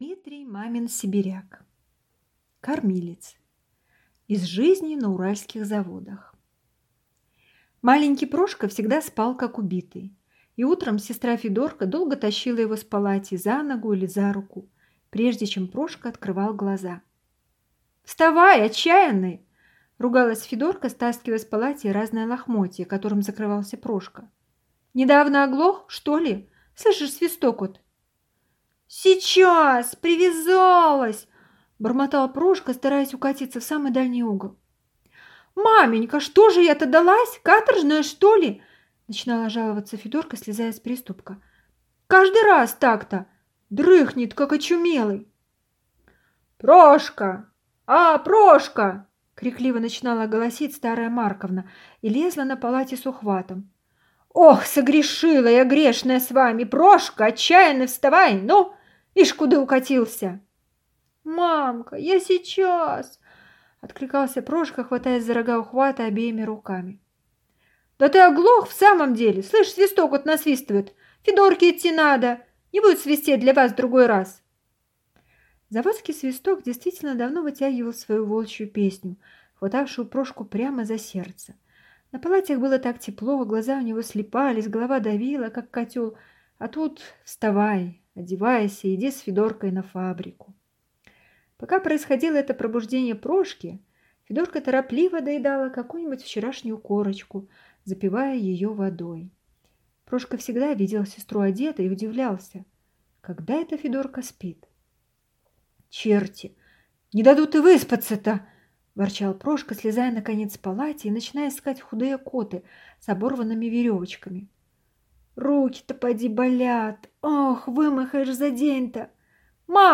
Аудиокнига Кормилец | Библиотека аудиокниг